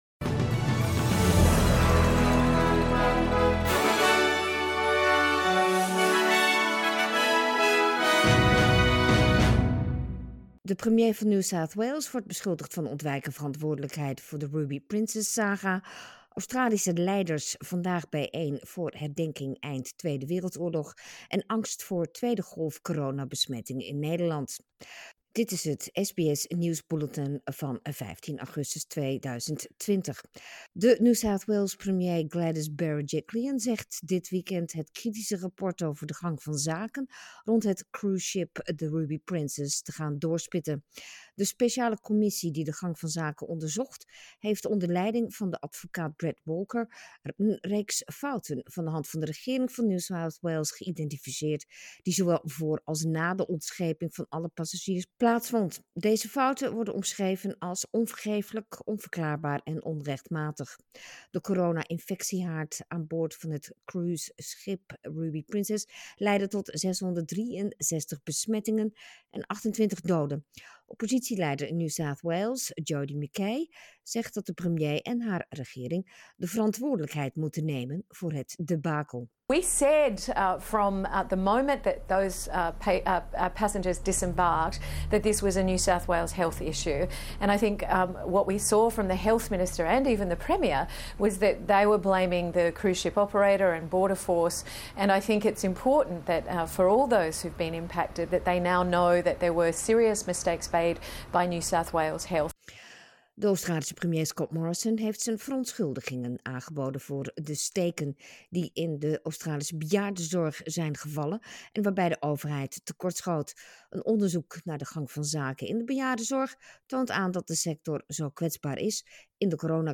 Nederlands/Australisch SBS Dutch nieuws bulletin zaterdag 15 augustus 2020